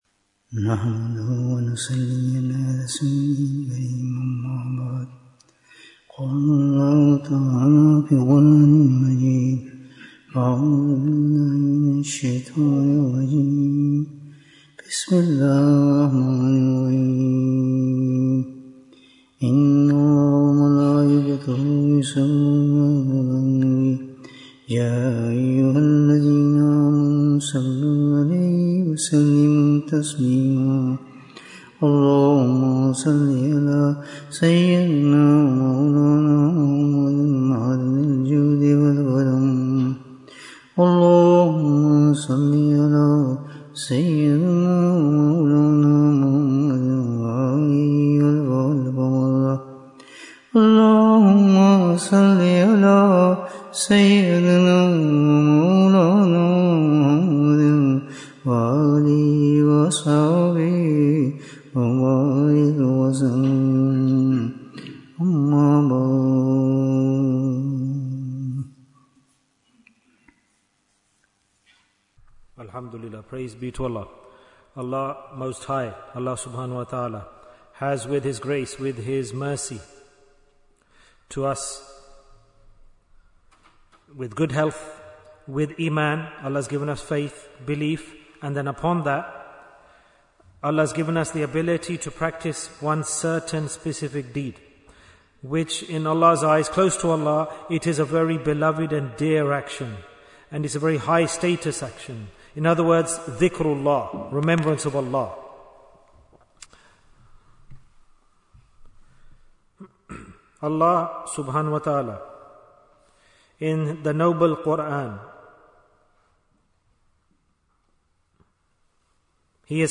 If You Want to Love Allah Bayan, 70 minutes21st August, 2025